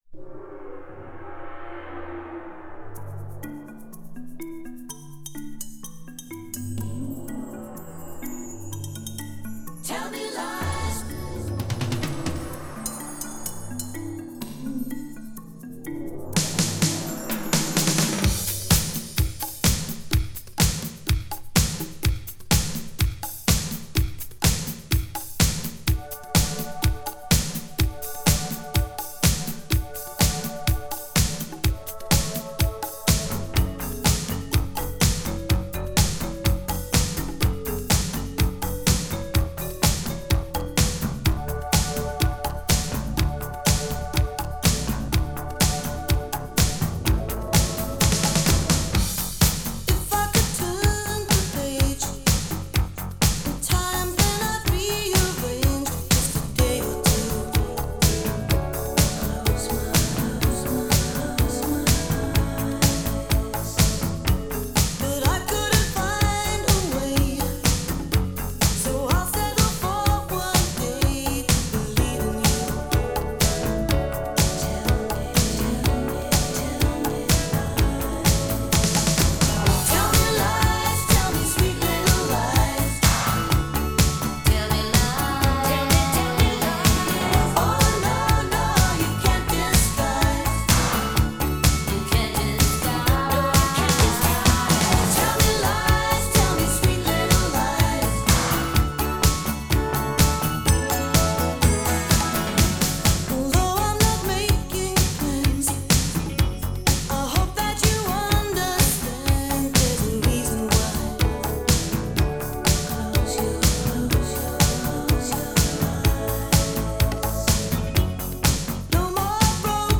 Vinyl rip